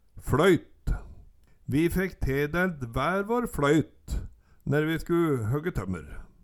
Høyr på uttala Ordklasse: Substantiv hankjønn Kategori: Landskap Tal, mål, vekt Attende til søk